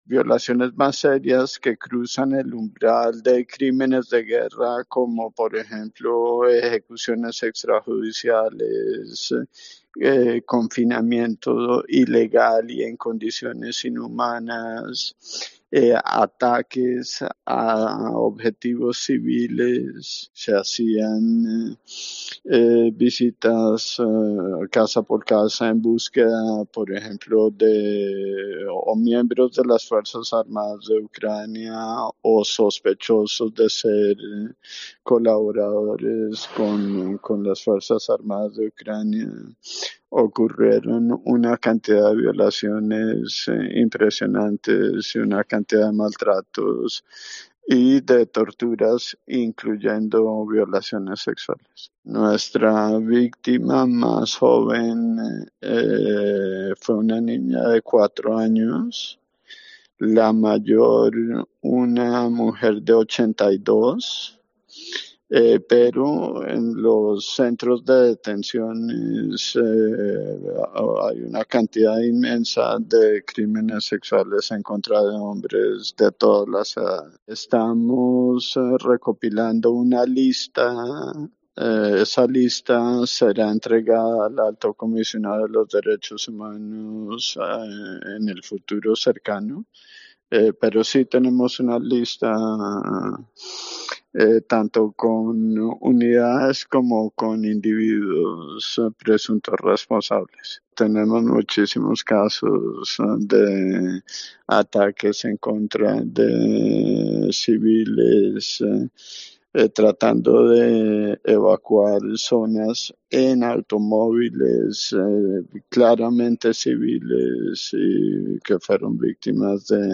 Un investigador de los crímenes de guerra en Ucrania, en COPE: "Tenemos una lista de responsables”